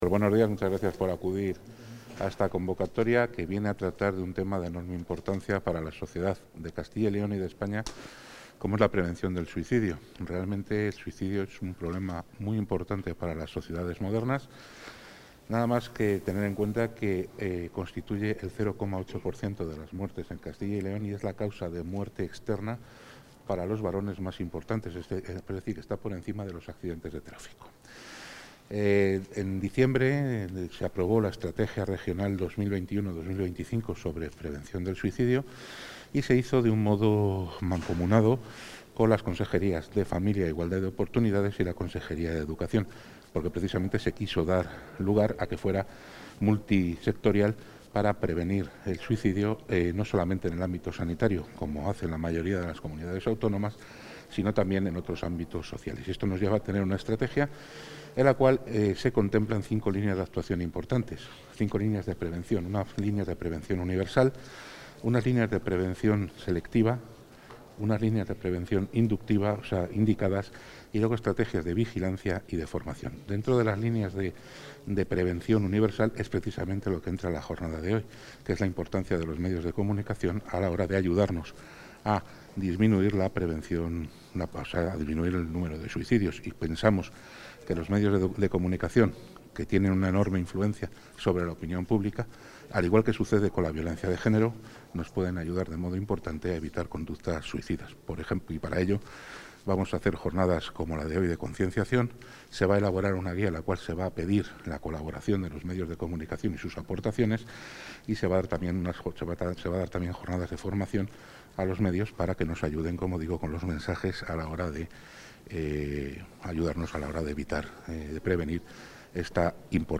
Intervención del consejero de Sanidad.
El consejero de Sanidad, Alejandro Vázquez, ha inaugurado hoy en Valladolid la jornada 'Estrategia de prevención de la conducta suicida en Castilla y León', dirigida a los medios de comunicación.